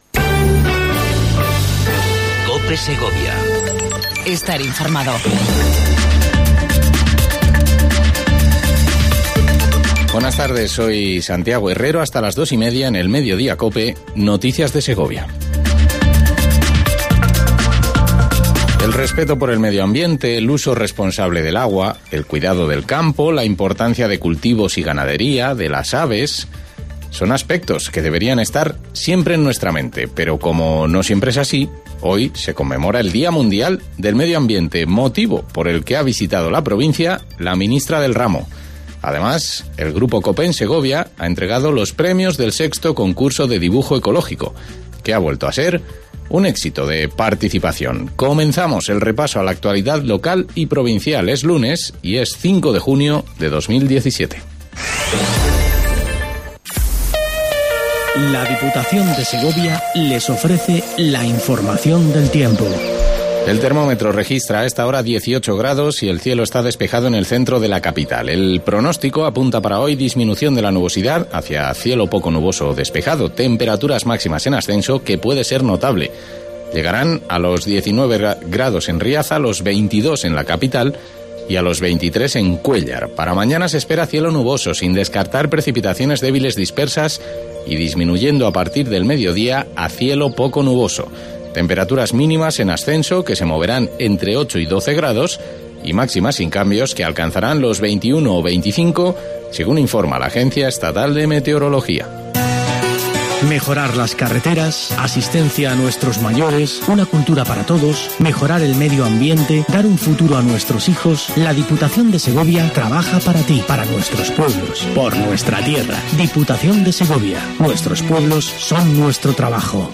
INFORMATIVO MEDIDIA COPE EN SEGOVIA 05 06 17